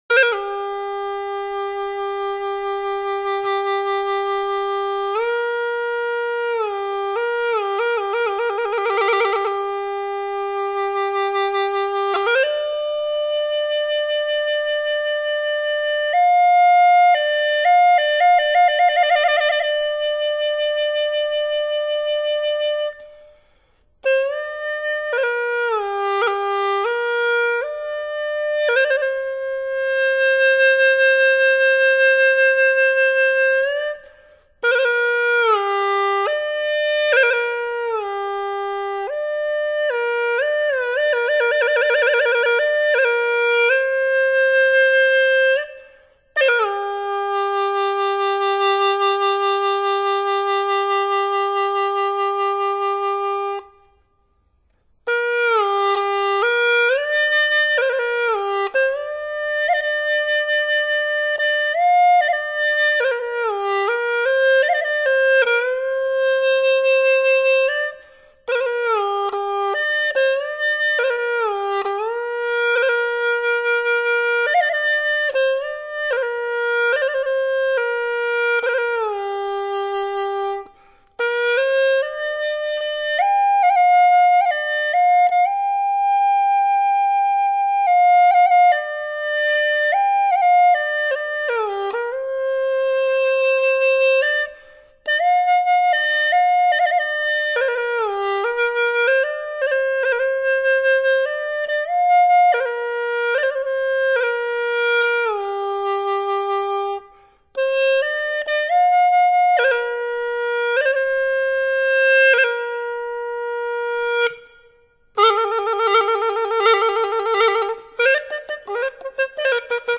器乐 独奏，中间不加任何装饰与配器，纯粹表现民族乐器的原汁原味。